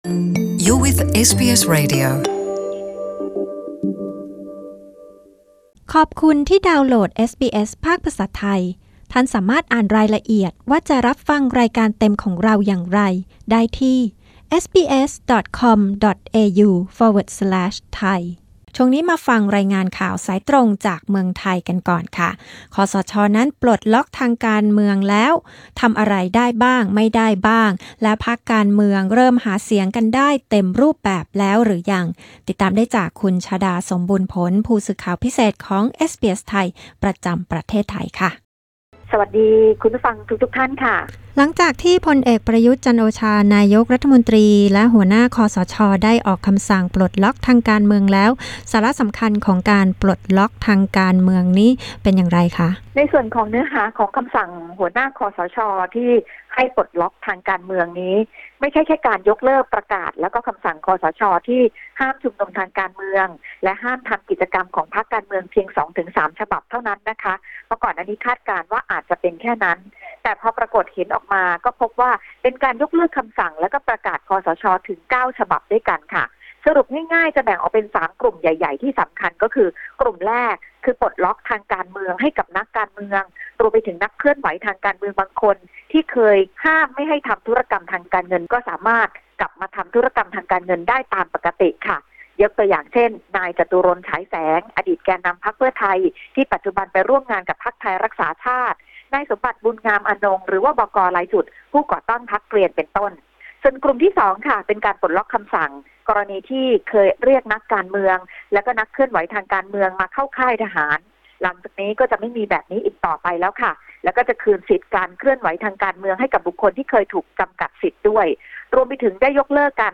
คสช. ประกาศปลดล็อกทางการเมืองแล้วบางส่วน อะไรที่ทำได้ อะไรยังทำไม่ได้ และพรรคการเมืองเริ่มหาเสียงกันได้เต็มรูปแบบแล้วหรือยัง เอสบีเอส ไทย มีรายงานสรุป